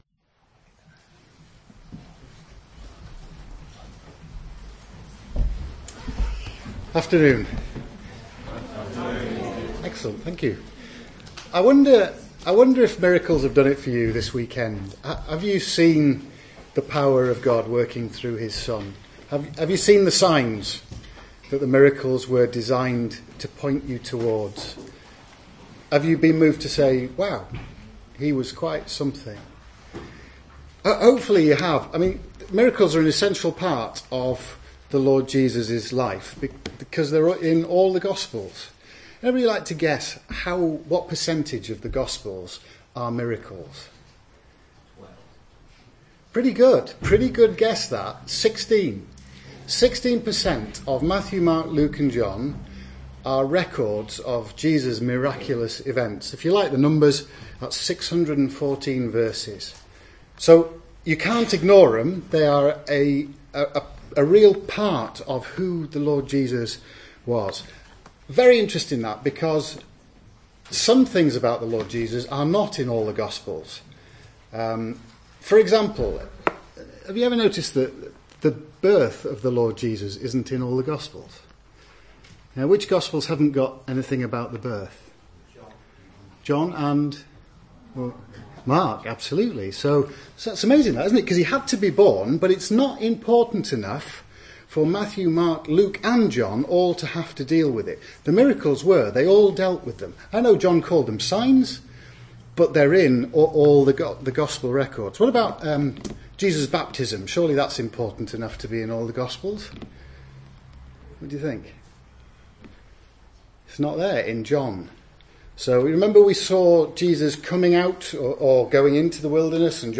Exhortations
youthweekend2014-5.mp3